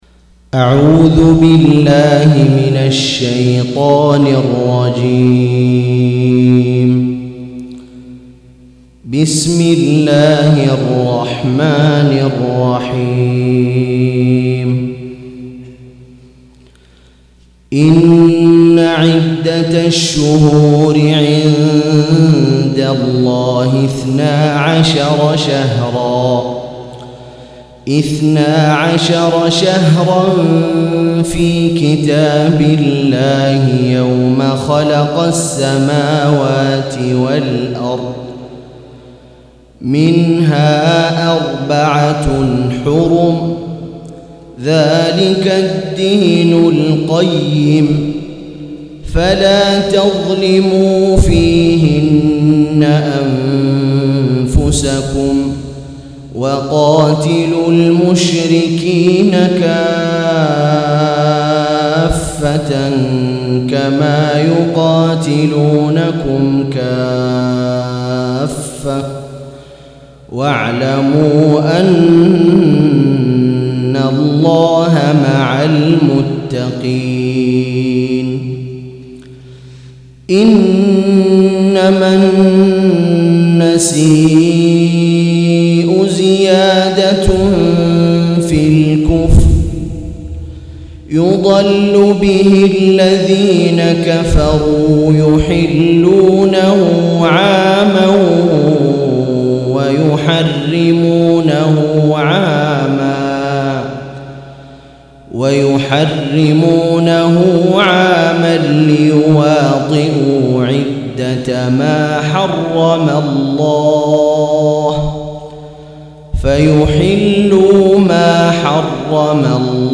183- عمدة التفسير عن الحافظ ابن كثير رحمه الله للعلامة أحمد شاكر رحمه الله – قراءة وتعليق –